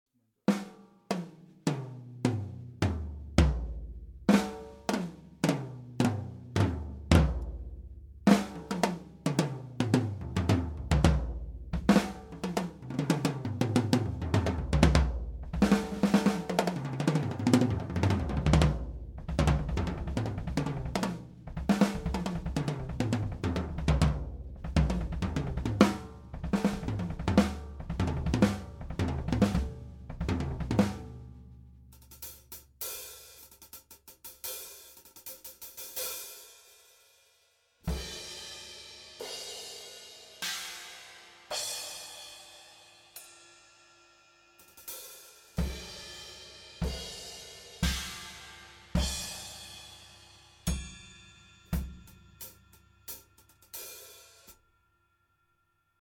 Das rechte Mikro war ca. 15cm über dem Standtom auf die Hihat schauend ausgerichtet.
Das linke Mikro war direkt von oben genau zwischen die Snare und die Bassdrum schauend ausgerichtet.
Die Signale der Overheads gingen in ein Soundcraft-Mischpult, EQs flat, Panning ca. 8:00 und 16:00 Uhr.
Der Raum ist ein zu ca. 2/3 mit Noppenschaum ausgekleideter Kellerraum von vielleicht 3m x 5m. Die Deckenhöhe ist leider nicht groß und wird durch ein ca. 20cm hohes Drumpodest noch weiter verringert - nicht ideal für Overhead-Aufnahmen. Überhaupt ist der Raum zwar akustisch zum Proben ganz gut, aber für Aufnahmen eigentlich zu trocken und in den Höhen recht gedämpft. Ich war überrascht, das die Technik trotzdem so gut funktioniert hat...
C414 - nur Overheads - Toms und Becken einzeln - flat
Glyn-Johns_C414_OH-solo_Toms-und-einzelne-Becken_flat.MP3